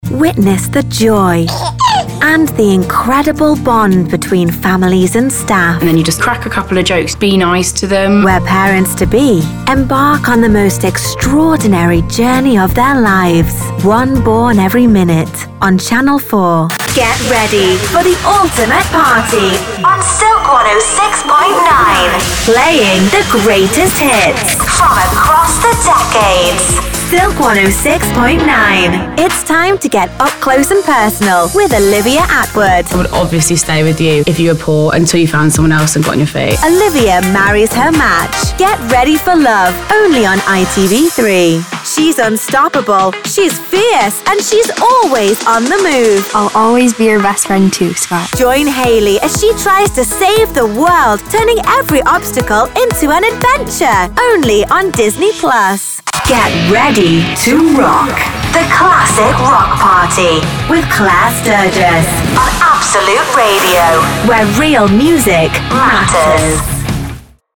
TV & Radio
Fun, fresh and vibrant.
She brings energy to any scripts that needs to ooze luxury with deep, warm, and silky tones, excite with heaps of enthusiasm, relate on a personal level, engage with technical information, and communicate serious topics with sincerity and understanding.